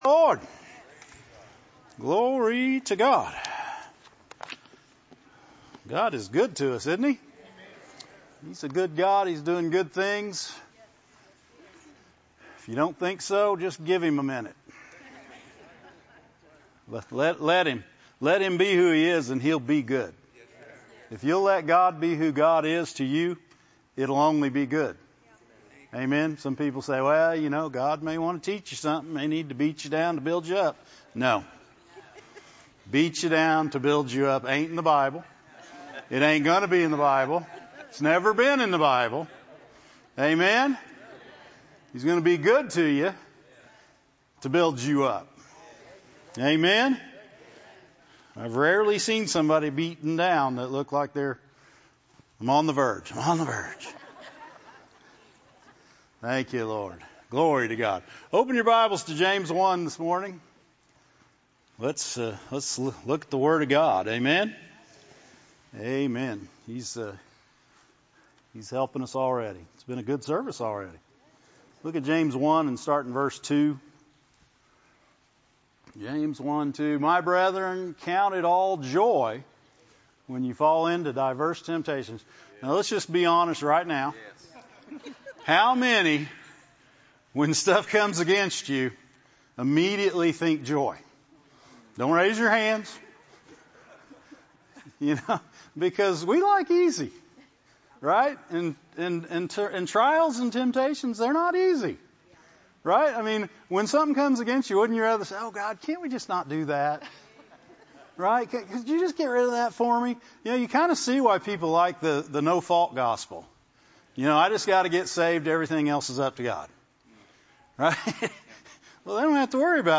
Religion & Spirituality, Christianity